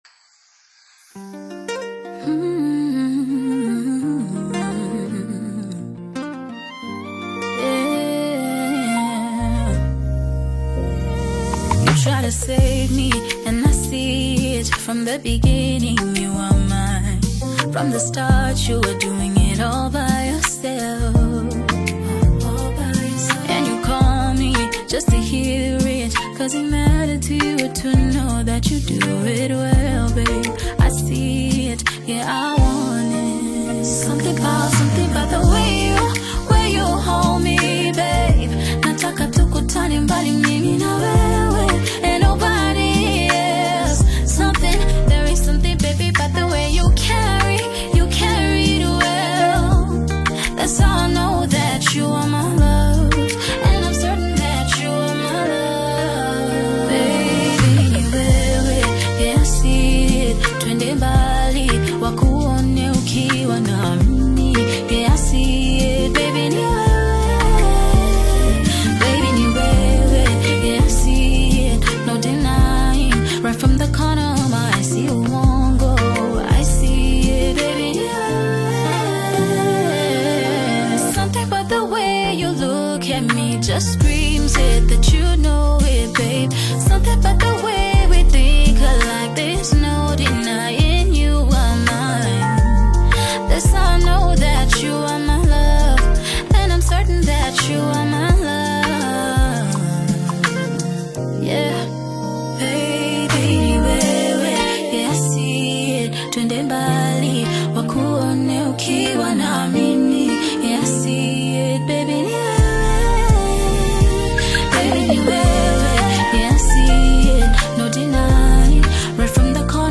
AudioKenyan MusicR&B
is a soulful Kenyan R&B/Soul single